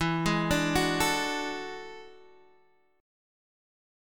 E11 chord {x 7 6 7 7 5} chord